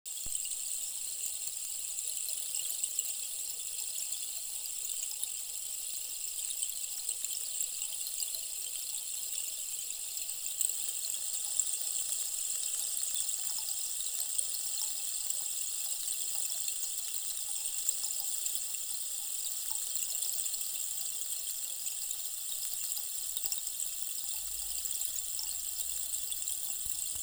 Harmonizující deštný sloup 90 cm dub 30 minut
Harmonizující deštný sloup z dubového dřeva je meditační a relaxační nástroj z naší vlastní výroby, který Vás provede světem klidu a vnitřní rovnováhy prostřednictvím jemných zvuků, jež evokují kapky tekoucí vody a šum deště.
• 💆‍♀ Fyzické uvolnění – jemný neustálý šum působí relaxačně na tělo a podporuje rozvolnění svalového napětí po náročném dni.
Zlepšení koncentrace je možné díky stejnoměrnému zvuku, který sloup vydává.
Jemný konstantní zvuk zlepšuje kvalitu spánku.